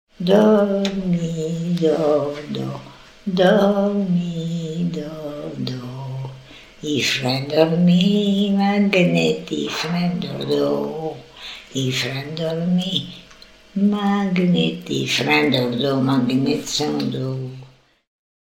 enfantine : berceuse